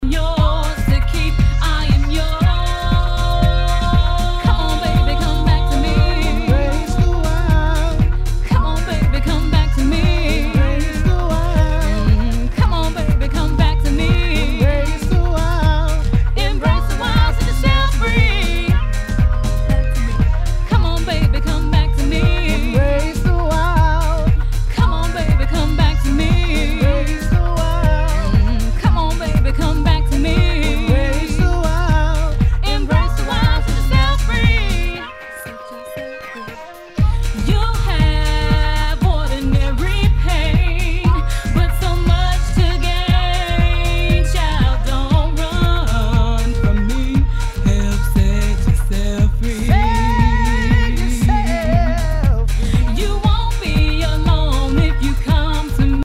HOUSE/TECHNO/ELECTRO
ナイス！ディープ・ヴォーカル・ハウス！